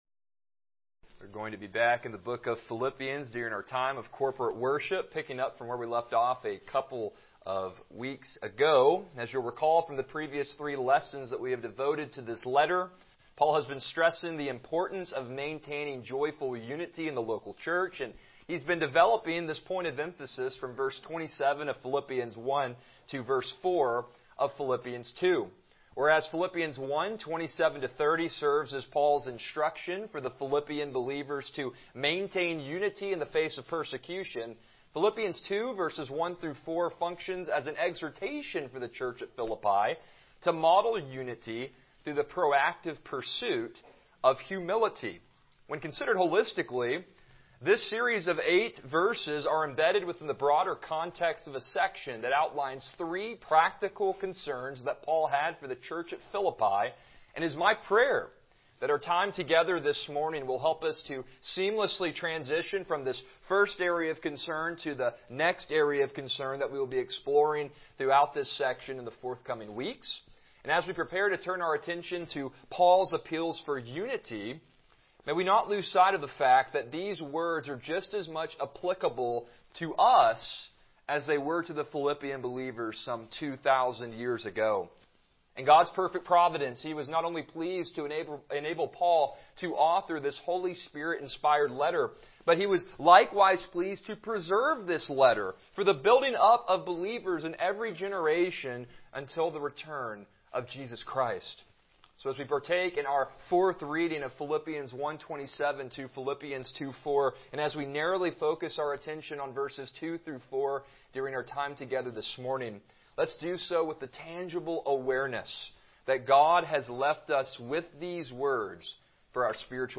Passage: Philippians 2:2-4 Service Type: Morning Worship